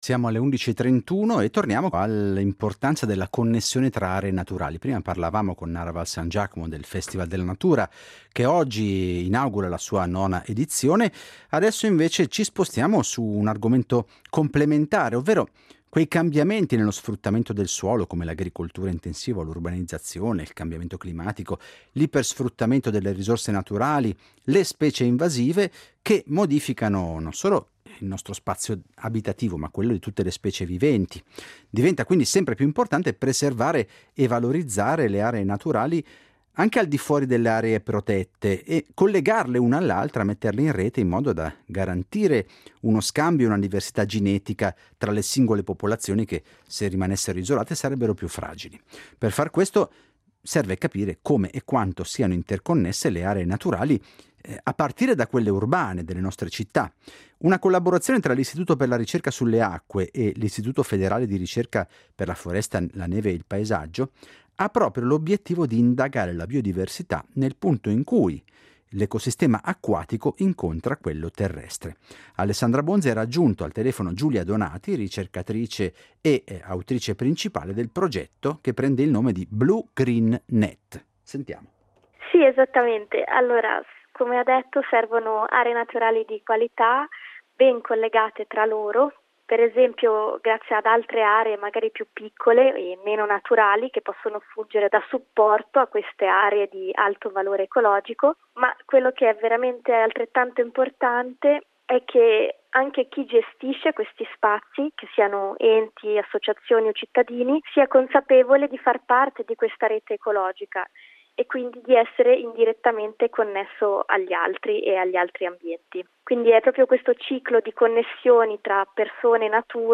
ha raggiunto telefonicamente